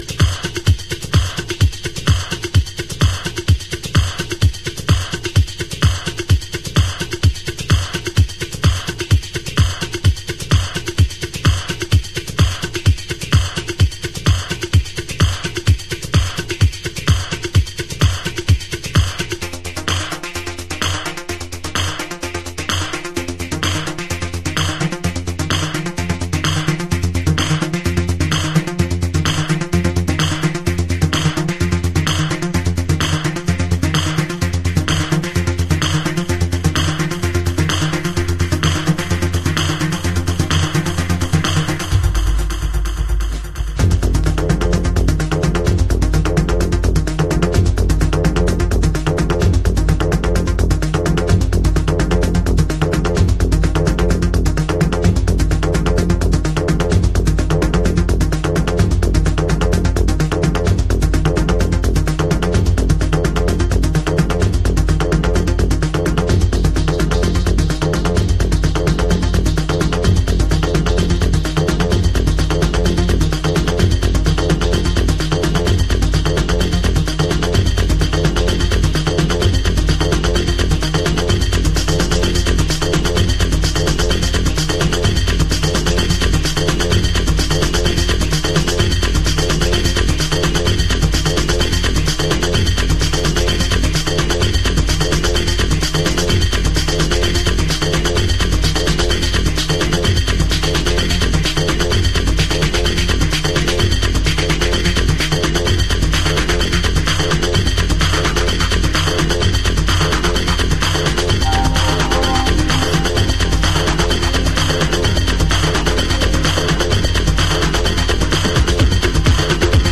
幾多のパーティーで死線をくぐり抜けて来た感じがひしひしと伝わってくる心拍数急上昇な危険サウンドです。
House / Techno